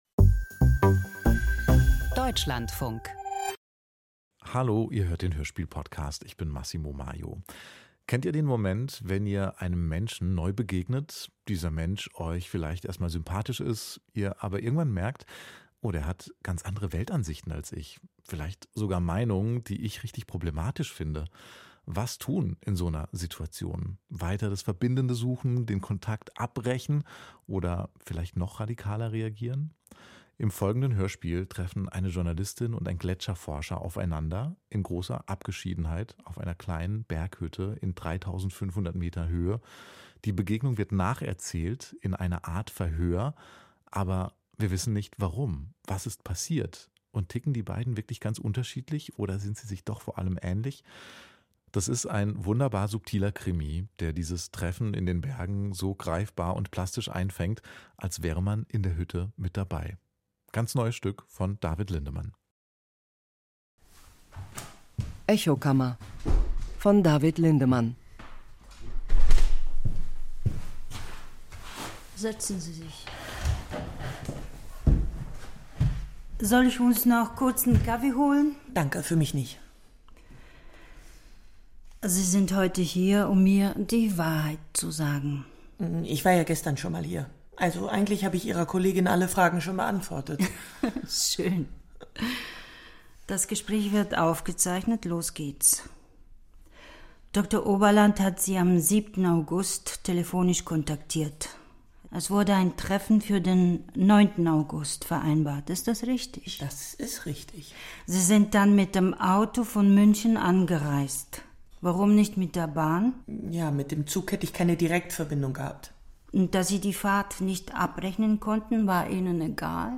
Hörspieldrama auf 3.500 Metern - Echokammer